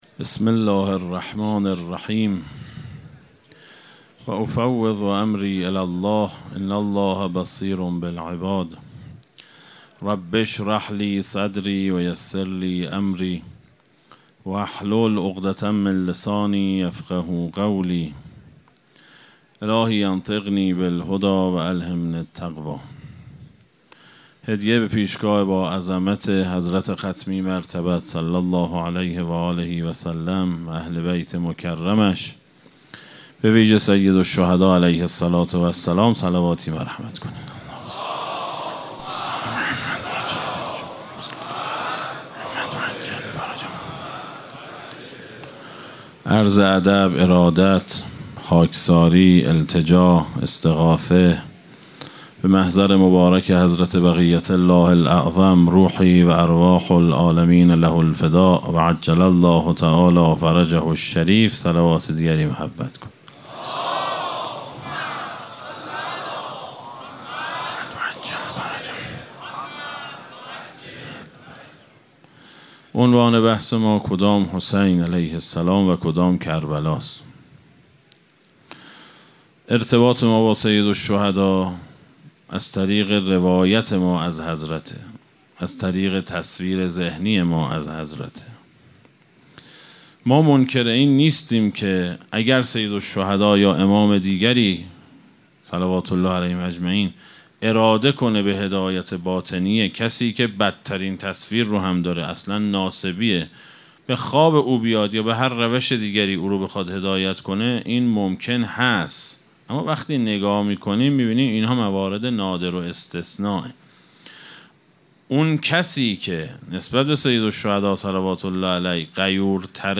سخنرانی
مراسم عزاداری شب تاسوعا محرم الحرام 1445‌‌‌‌‌چهارشنبه 4 مرداد ۱۴۰2 | 8 محرم ۱۴۴۵‌‌‌‌‌‌‌‌‌‌‌‌‌حسینیه ریحانه الحسین سلام الله علیها